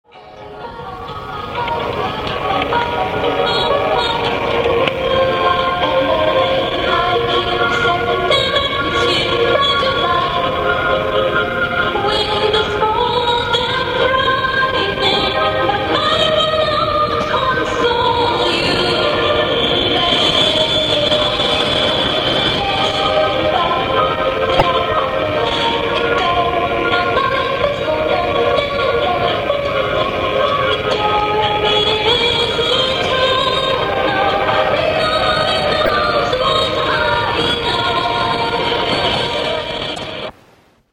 Песня на английском. Качество конечно не очень, но разобраться все-таки можно)
да,качество жуткое...а голосок ничо)